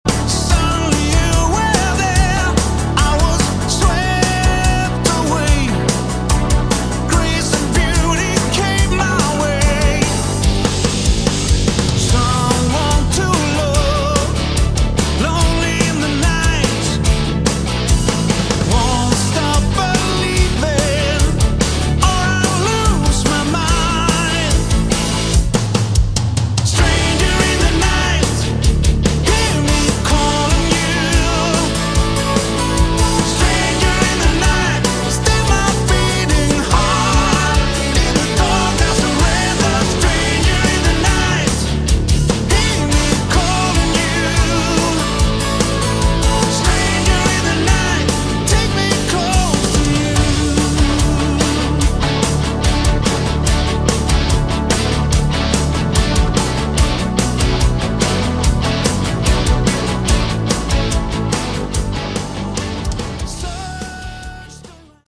Category: Melodic Rock
lead vocals
guitar, bass, keyboards, backing vocals
drums, additional Keyboards, backing vocals